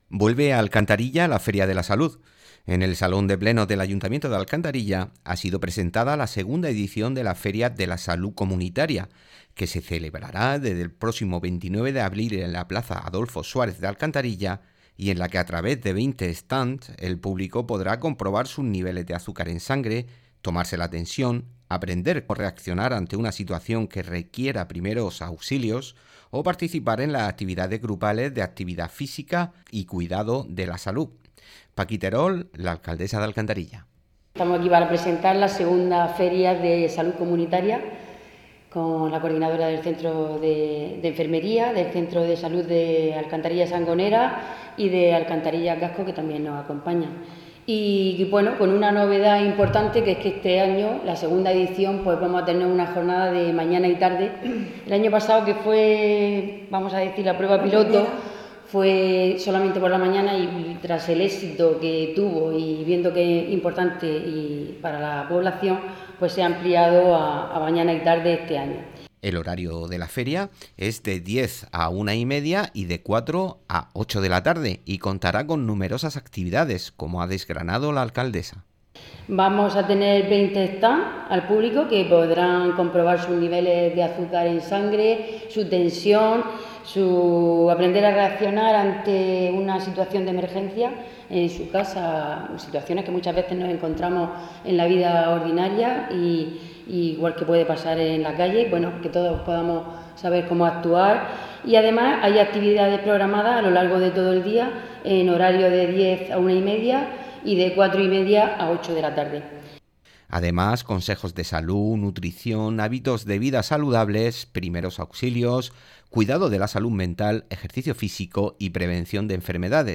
Escucha el audio de la noticia con las voces de sus protagonistas.